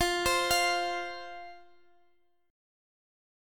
F5 Chord (page 2)
Listen to F5 strummed